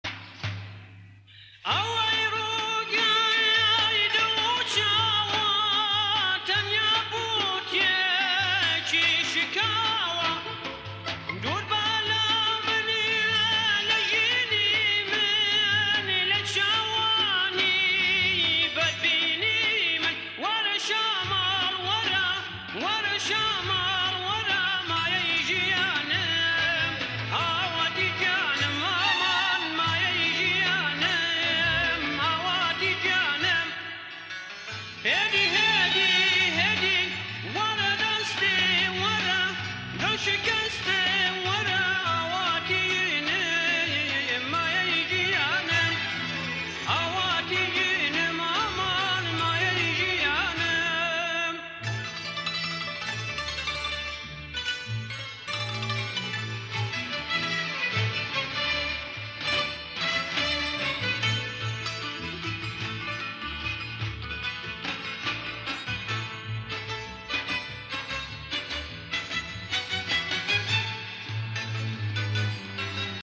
گۆرانی کوردی